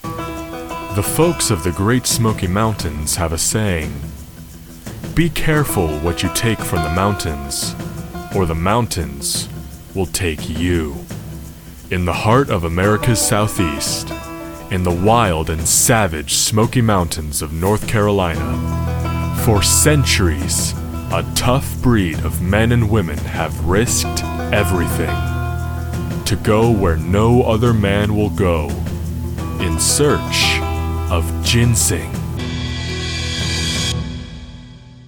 An American young adult voice actor with a deep voice specializing in narration
Documentary
Standard North American
Young Adult